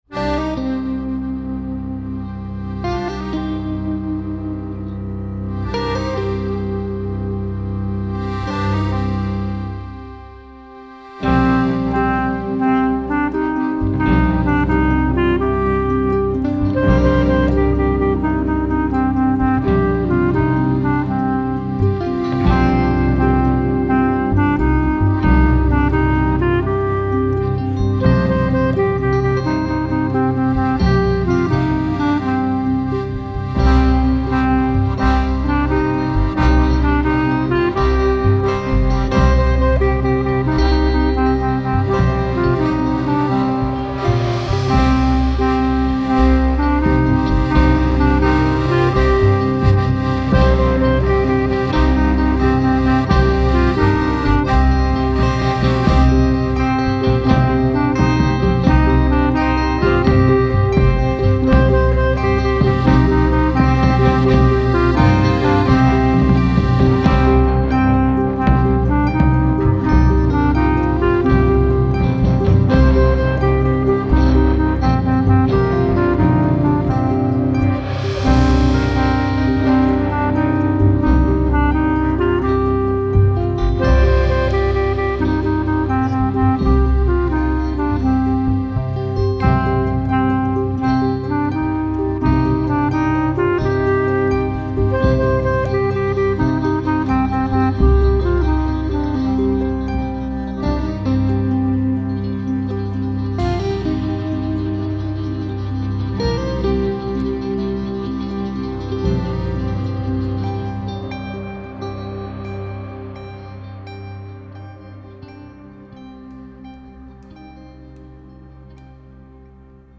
Row your Boat - Moll - Playback